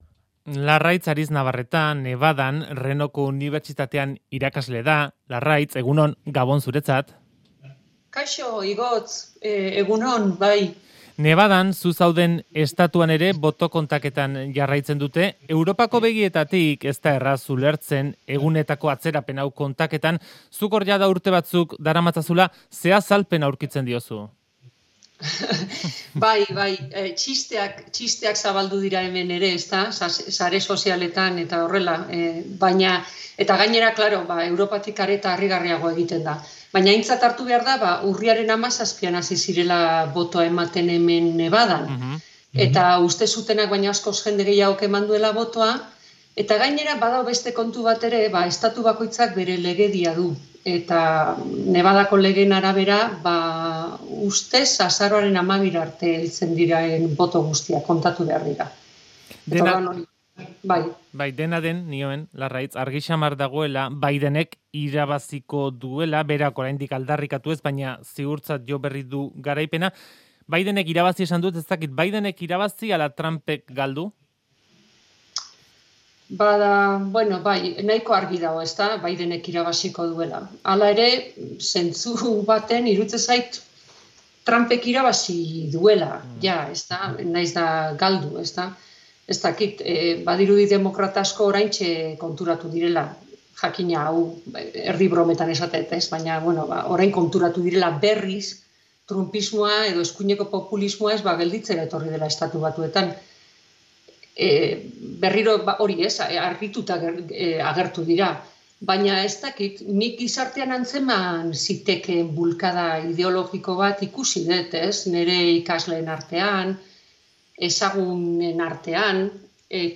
elkarrizketan